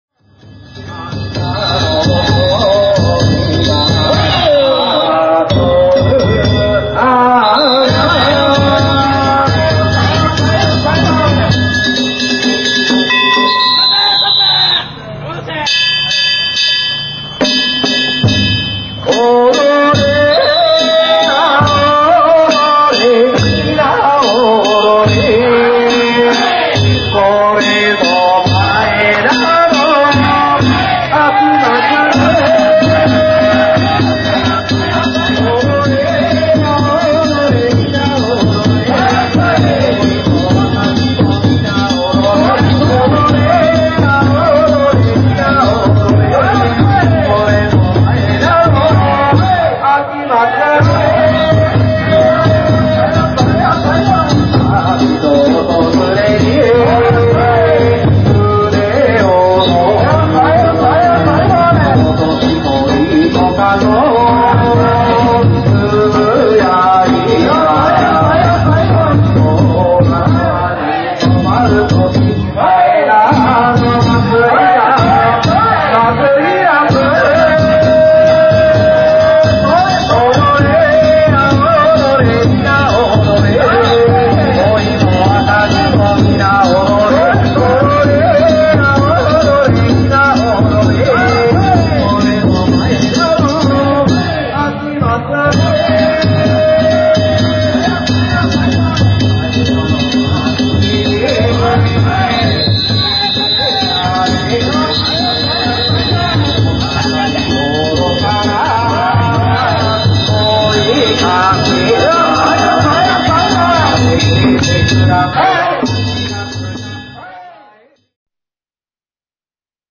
平成２８年２月２８日、大阪狭山市の前田地車お披露目曳行を見に行ってきました。
向きが変わると曳き唄も変わりました。
曳き唄唄いながら東へ向かいます。